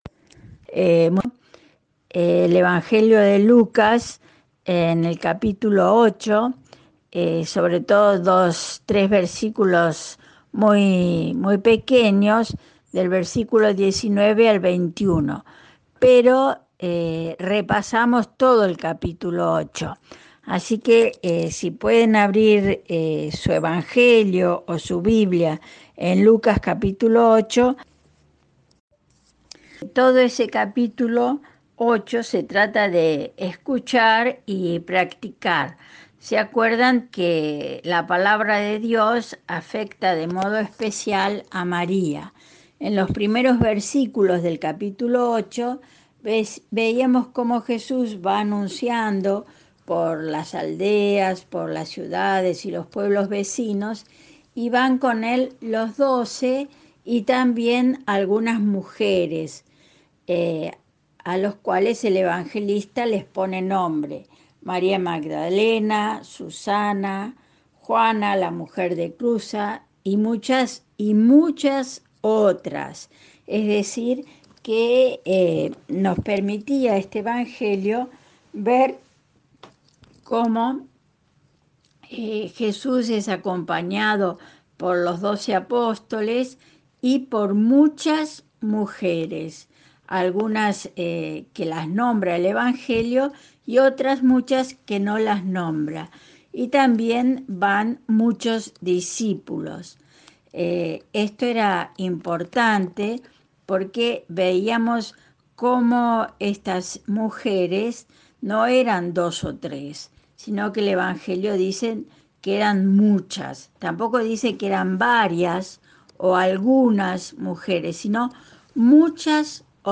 Saboreando la Palabra es un espacio de estudio y reflexión de la Palabra de Dios que se lleva adelante en la comunidad eclesial Virgen de la Asunción de El Palomar (Buenos Aires). En 2020, para continuar los talleres bíblicos desde casa, los encuentros se realizaron vía WhatsApp.